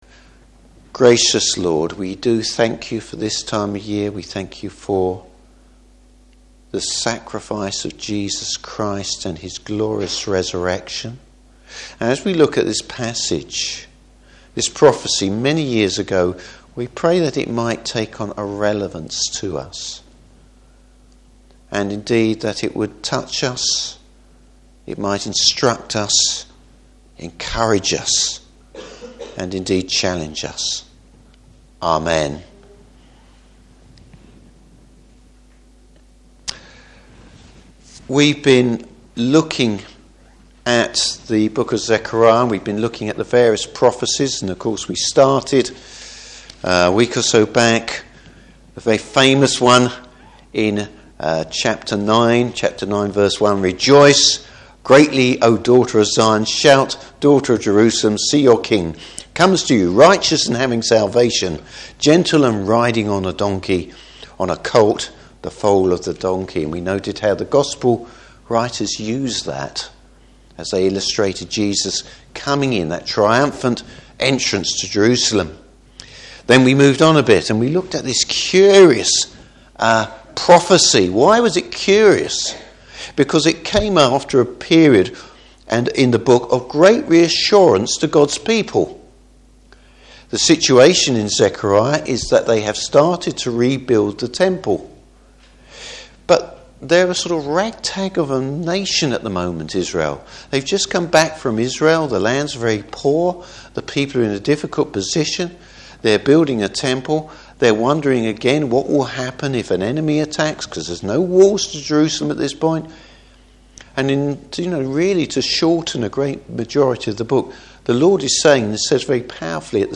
Service Type: Easter Day Evening Service.